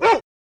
Roland.Juno.D _ Limited Edition _ GM2 SFX Kit _ 13.wav